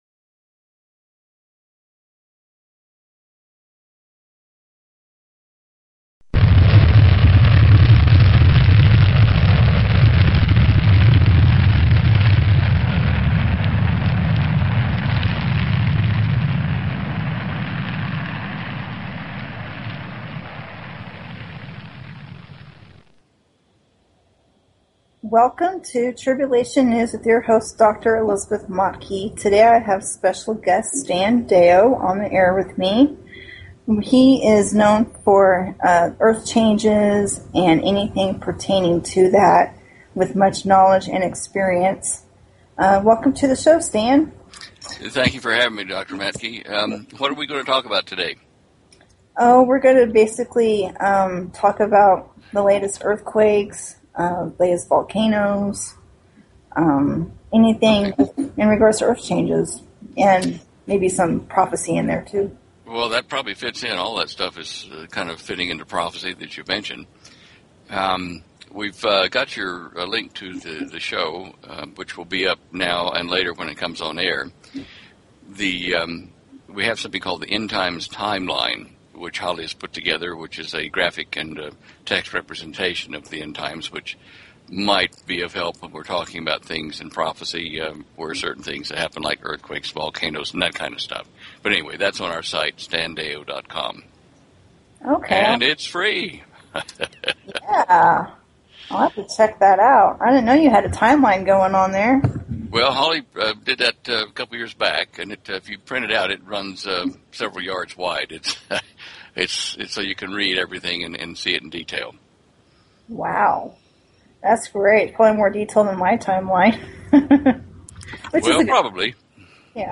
Talk Show Episode, Audio Podcast, Tribulation_News and Courtesy of BBS Radio on , show guests , about , categorized as